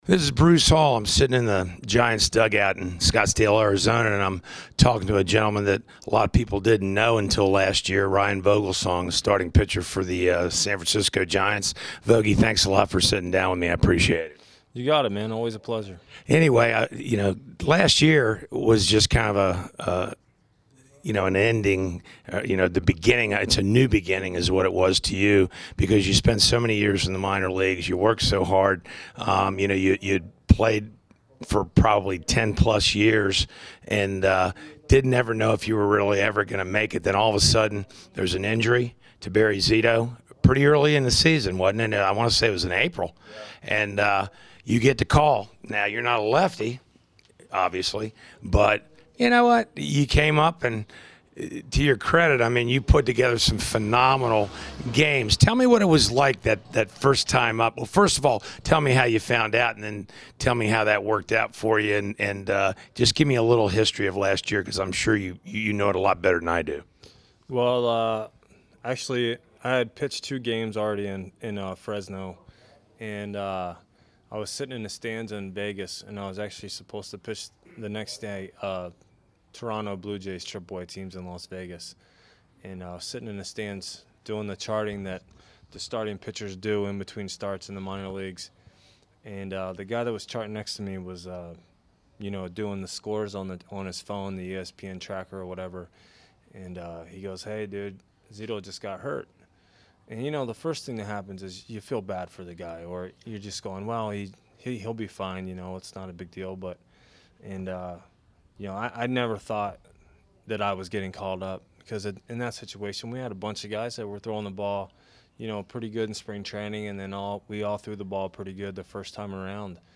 This is an interview I had with Ryan at the end of spring training this year.